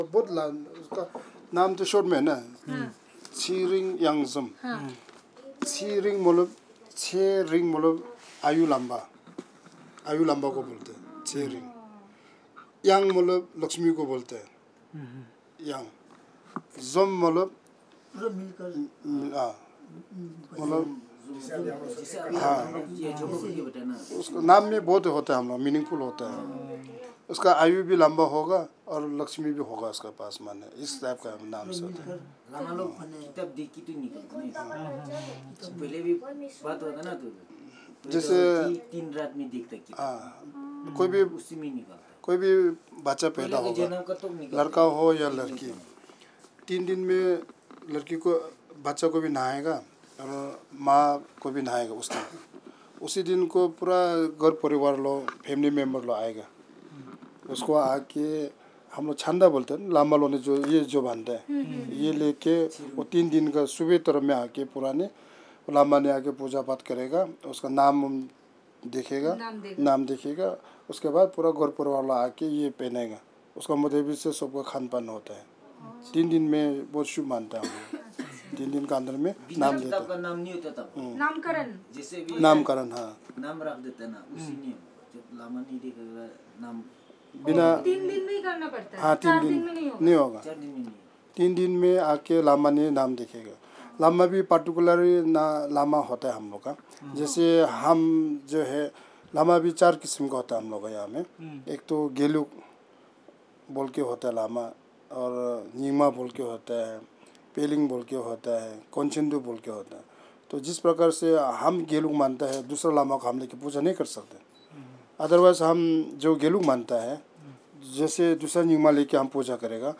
Conversation about names and naming ceremonies in the Monpa community